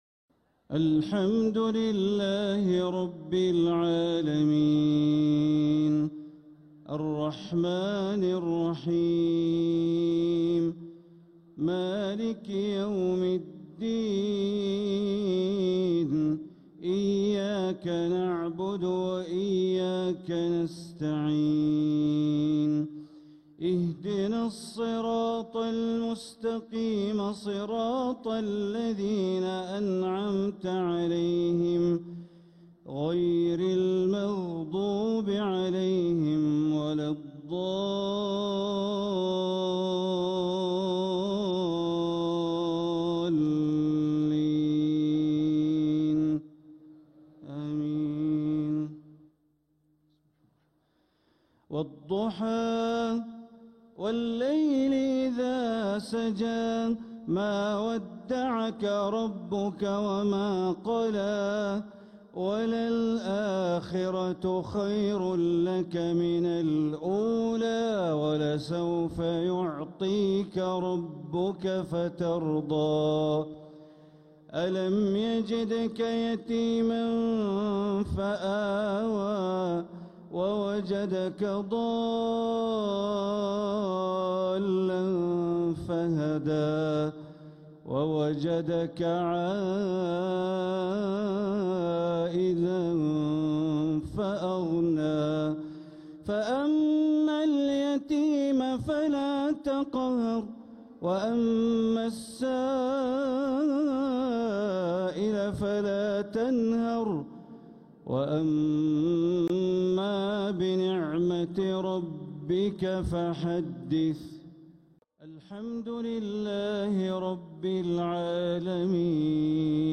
تلاوة رائعة لسورتي الضحى والشرح للشيخ بندر بليلة | عشاء ٩ رمضان ١٤٤٧هـ > 1447هـ > الفروض - تلاوات بندر بليلة